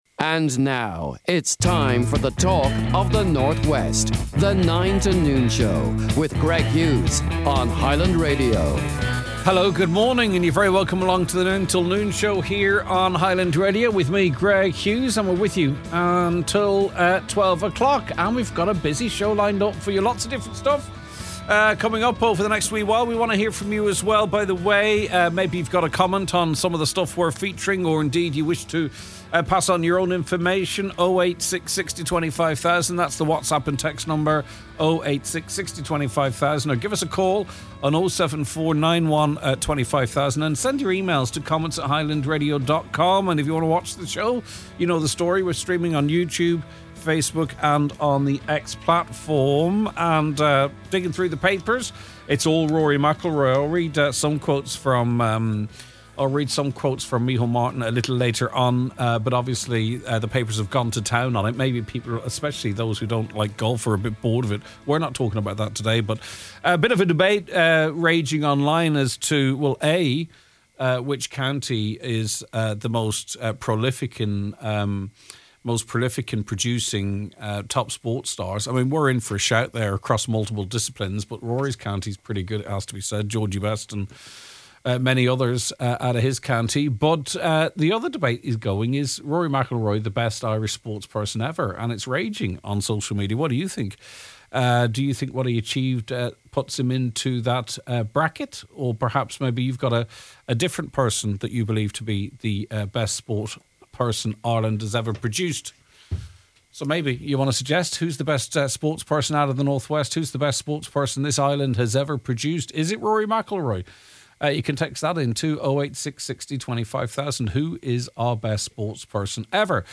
The Nine Til Noon Show is broadcast, live Weekdays from 9am to 12noon- the podcast is below: